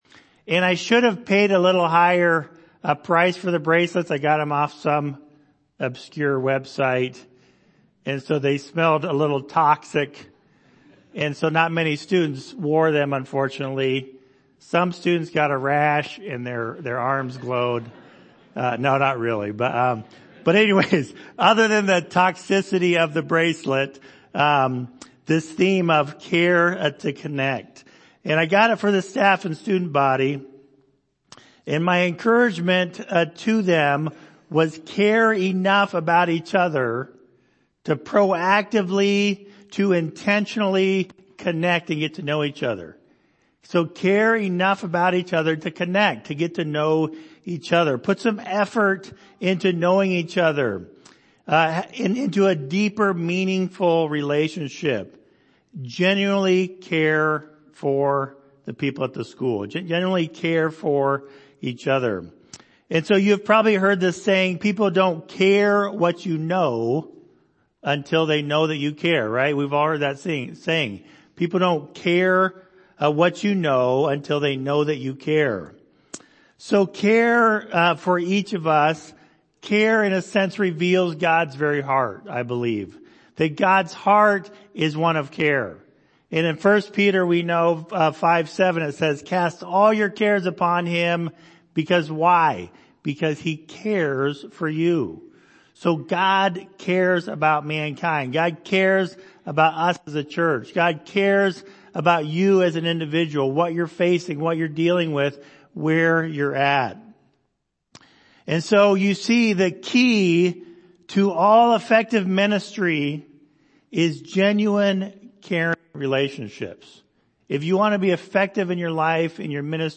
Galatians Passage: Galatians 4:12-20 Service Type: Sunday Morning « Law & Grace Staying Where Christ Put Me